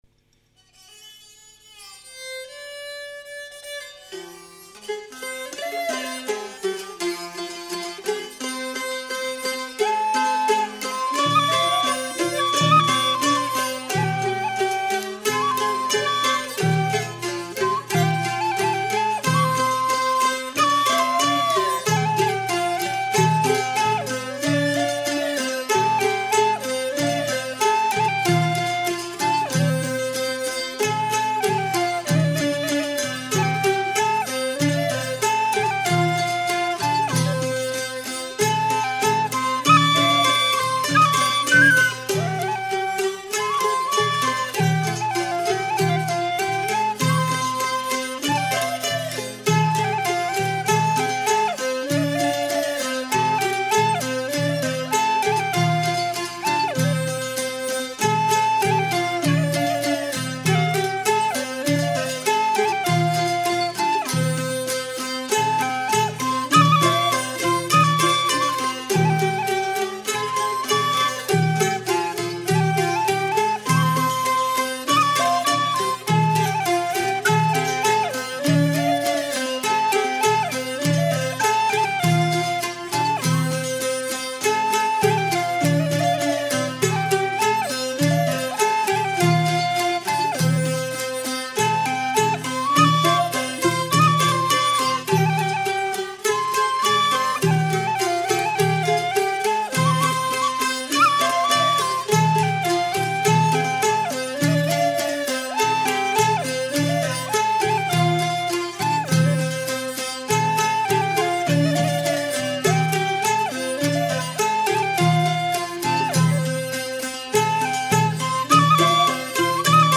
Field recordings
Recordings from Northern Thailand and Northern Guatemala.
String band music from the hills near Lampang, played by workers and friends at the Thai Elephant Conservation Center. This is known as a Salah San Seung ensemble, featuring the saw fiddle, seung lute, san flute, and frame drums.
You will hear these recordings playing constantly on the PA system at the Thai Elephant Conservation Center.